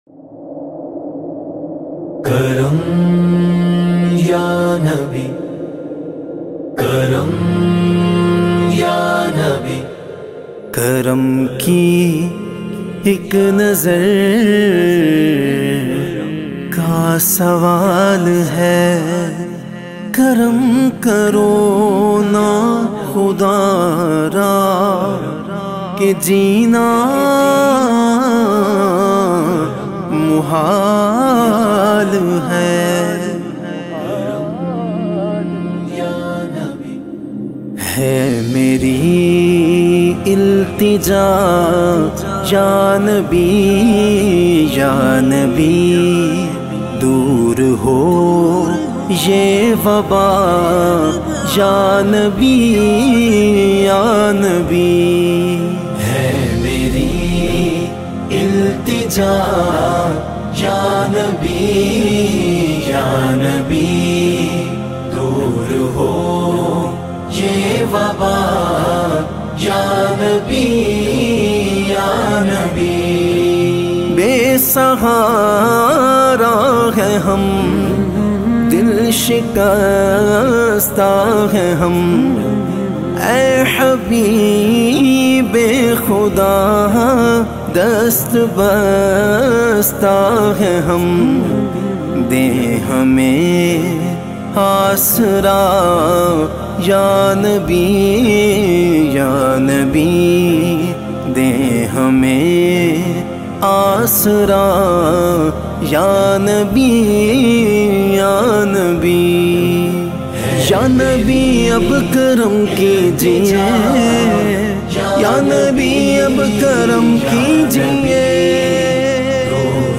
naat khuwan